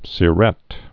(sĭ-rĕt)